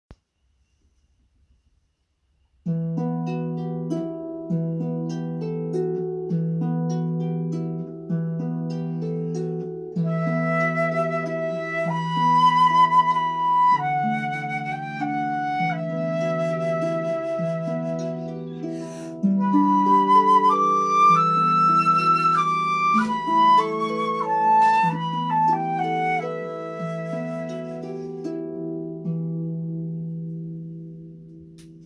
This makes a beautiful duet for any performance!